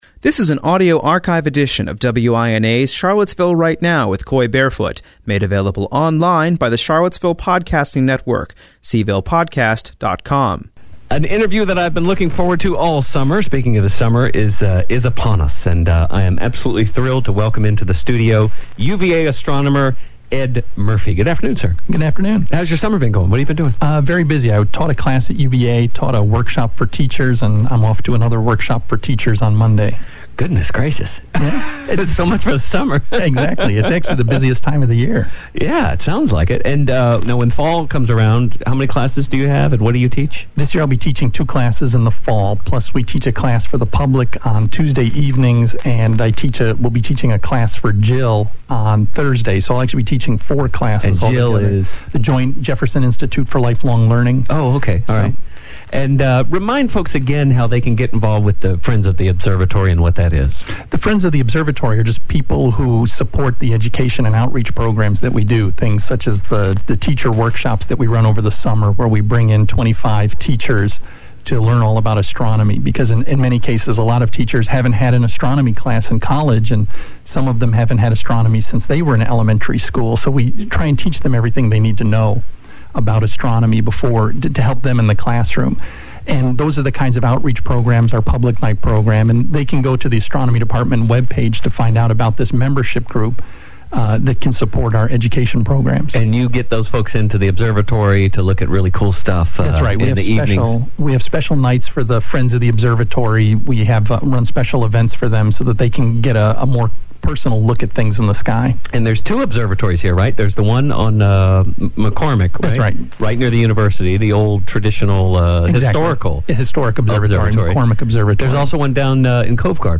Examples include Voyager crossing out of the solar system, charged particles in Saturn’s magnetic field, plusars, and even the sound of the Big Bang.